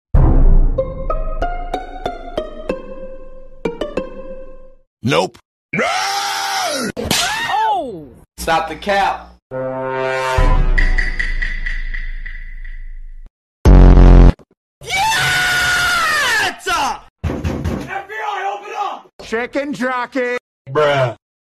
10 random memes sounds part sound effects free download